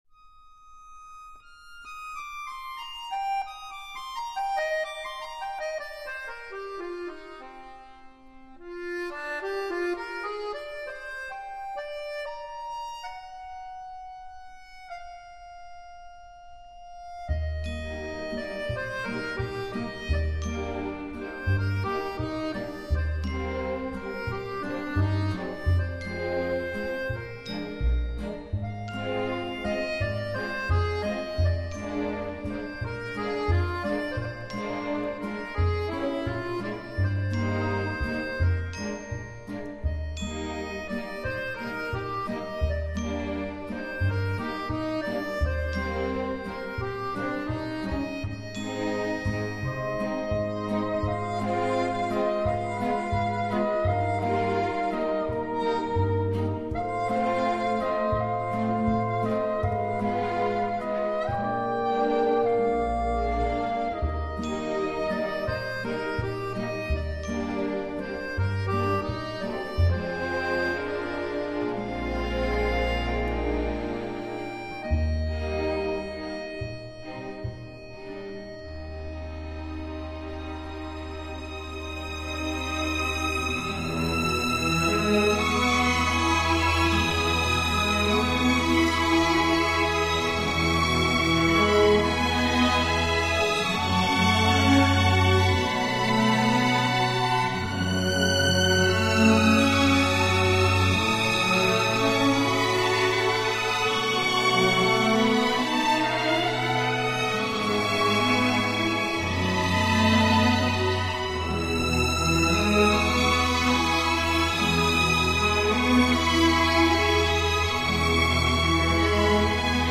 世界三大轻音乐乐团之一
轻松的音乐旋律，给中国人民传达了一种全新的音乐欣赏理念。